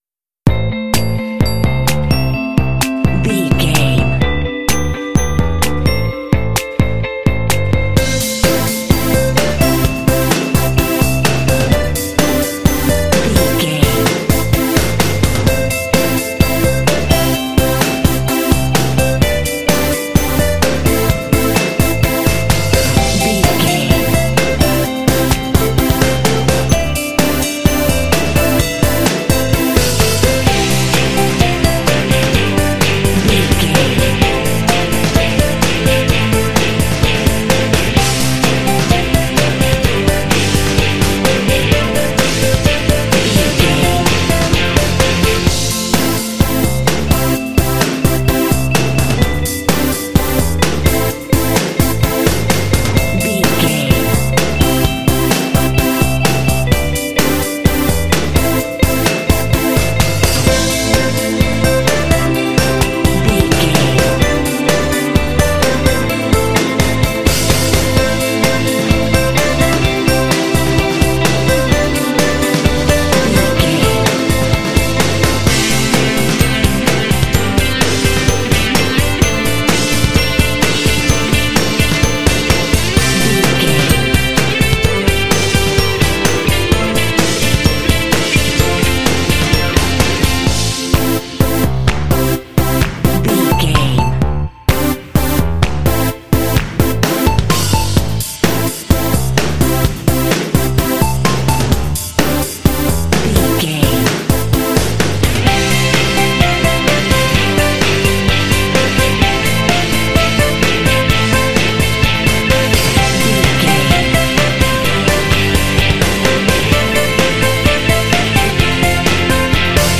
Aeolian/Minor
fun
cool
calm
driving
strings
bass guitar
synthesiser
piano
drums
alternative rock
indie